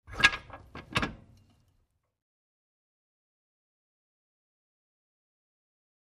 Stage Curtain: Handle Movement.